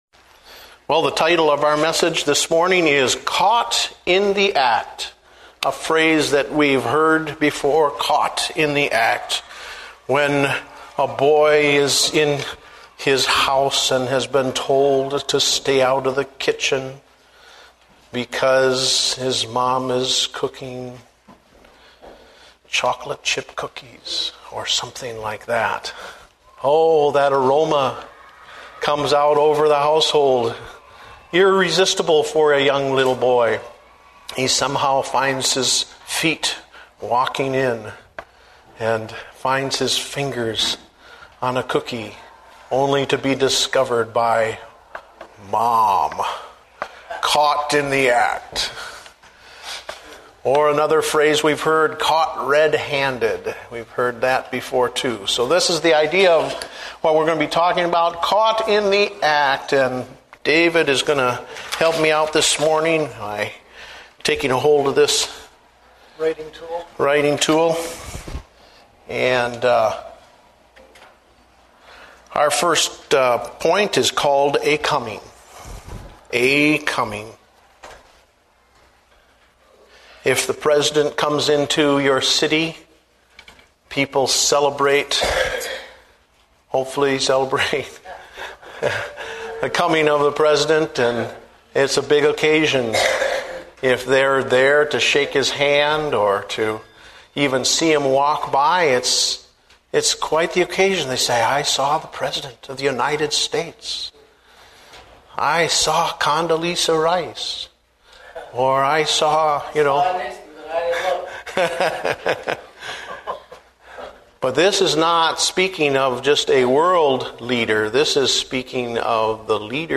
Date: September 21, 2008 (Adult Sunday School)